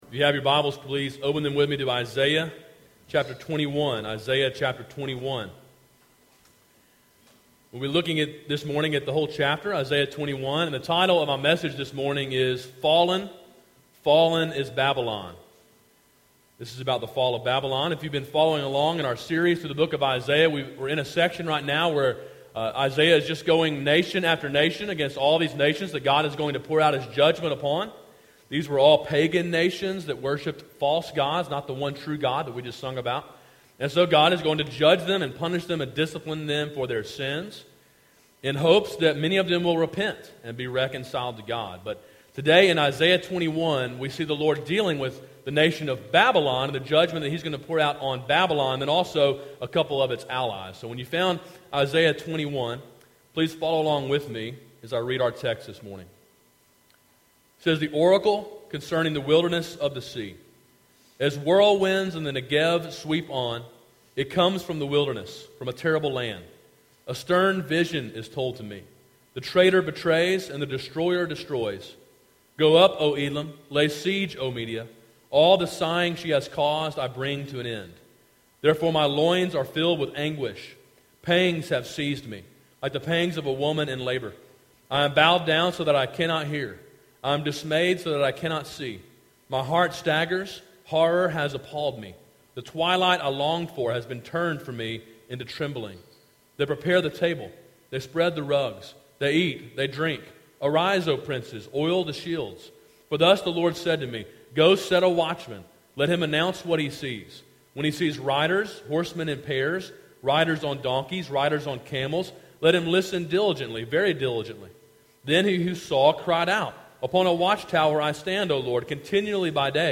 Sermon in a series on the book of Isaiah.